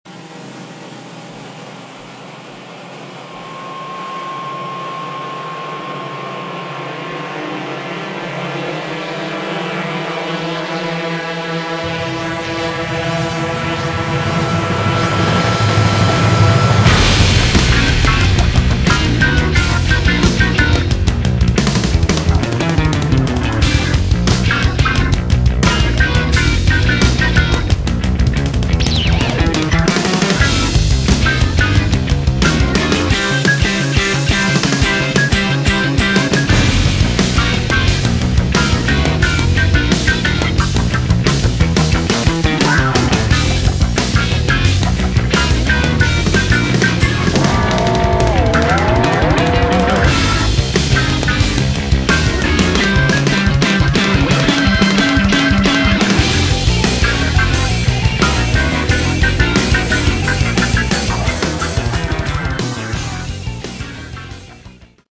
Category: Prog Rock
guitars